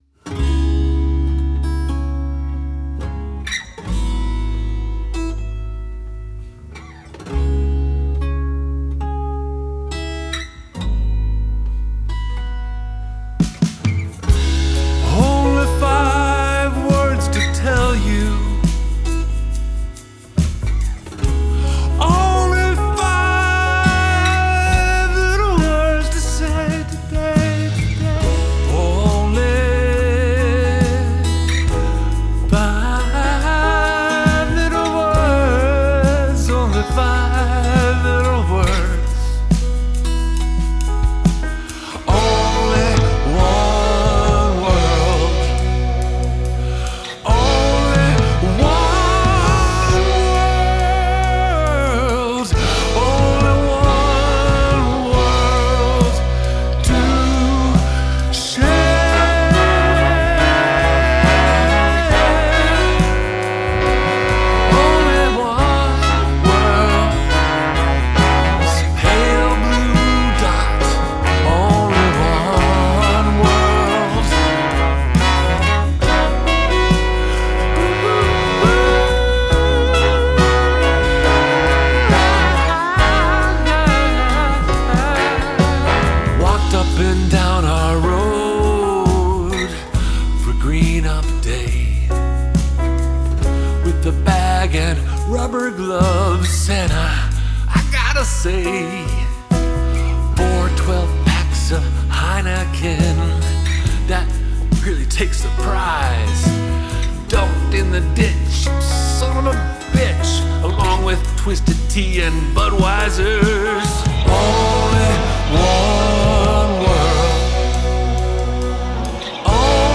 vocals, acoustic guitars, electric guitars, piano
drums, percussion
bass
trumpet
trombone
tenor saxophone
baritone saxophone
backing vocals, blues harp